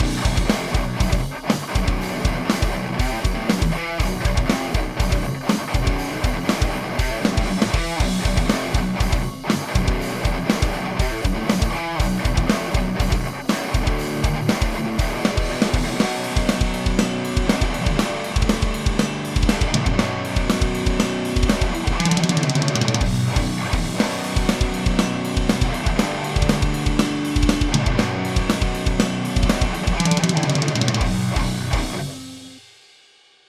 Ho creato due esempi audio per questa parte della channel strip, uno con TS e VH4 attivi
Gtrs_TS_VH4.mp3